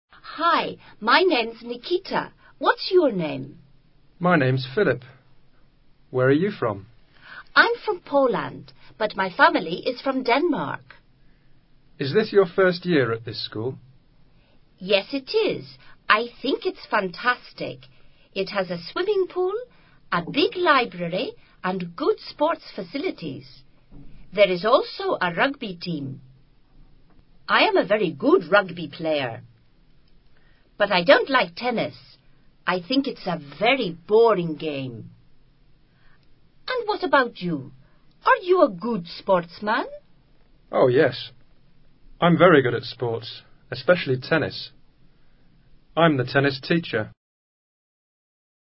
Este sencillo diálogo permite reforzar preguntas características de las primeras presentaciones personales, como what's your name? y where are you from?, los modelos de respuesta adecuados, vocabulario relacionado con la vida personal y social, deportes y hobbies.